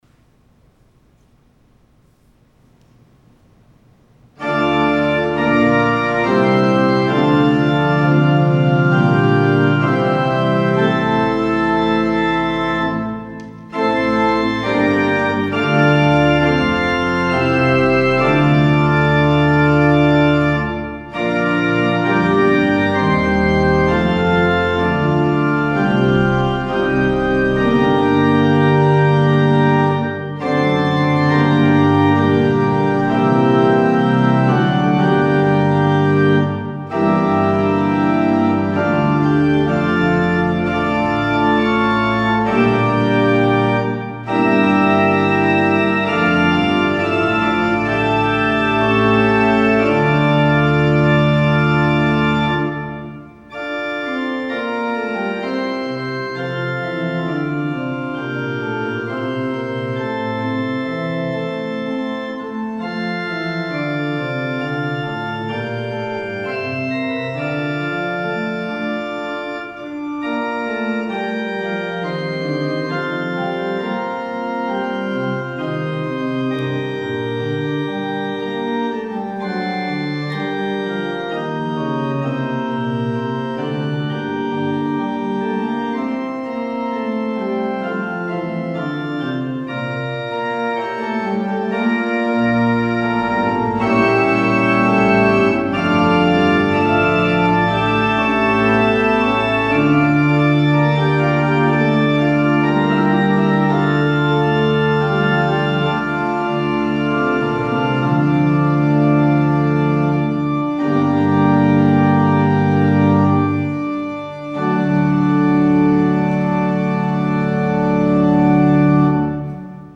Thank you for joining us for worship online!